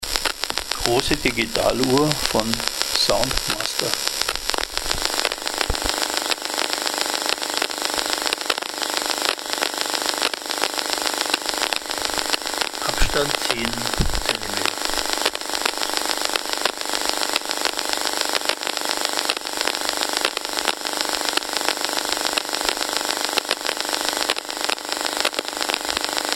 STÖRQUELLEN AUDIODATENBANK